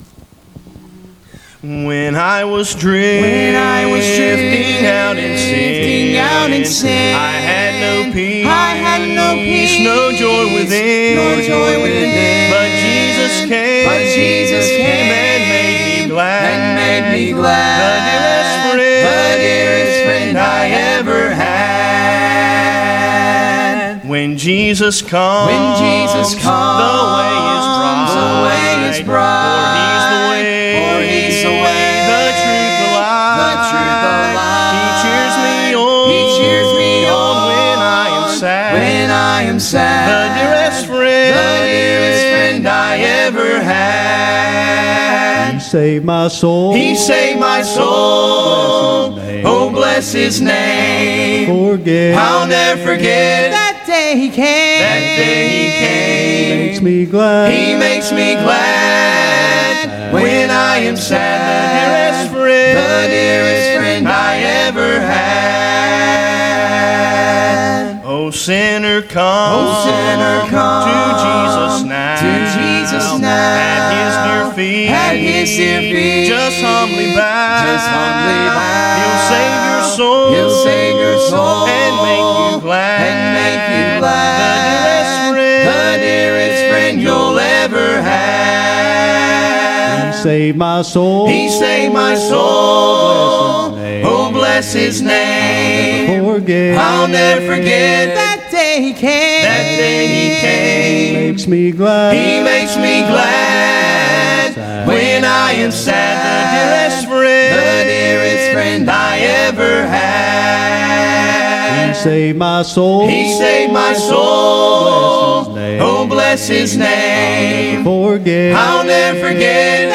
Sermons Archive • Page 131 of 196 • Fellowship Baptist Church - Madison, Virginia